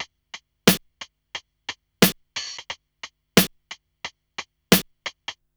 RNB89BEAT3-R.wav